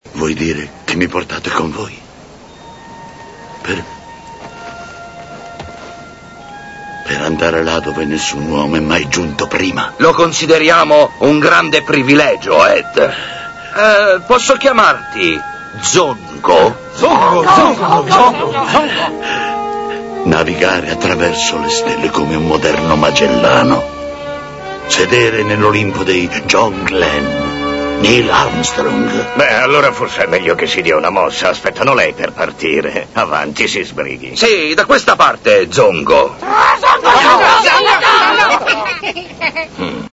Con sullo sfondo un cartello con il classico 'Beam me up' il cattivo Ed minaccia gli alieni. Verra' nominato ambasciatore e si esaltera' sulle note di Star Trek!